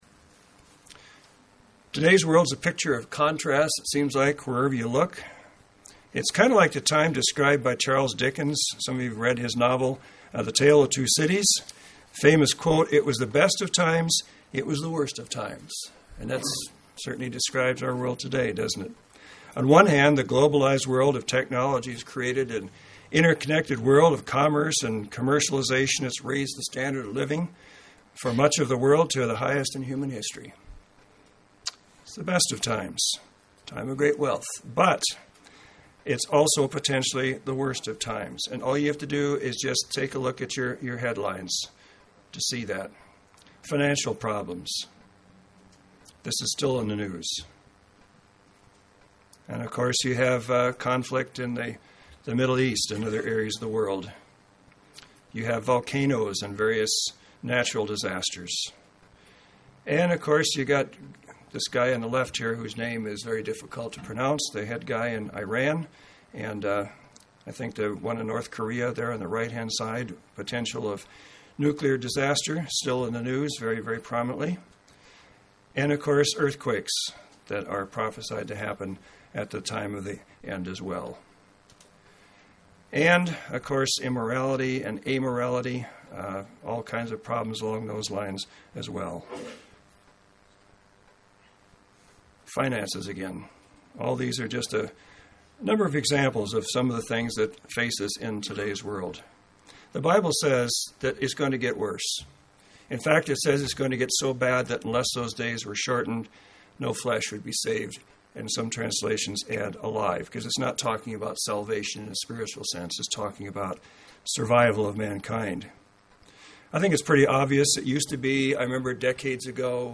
This Kingdom of God seminar is to help you understand what you can do in advance for this coming kingdom, to make a difference in your life today, and prepare for a role in God’s coming Kingdom.
Given in Central Oregon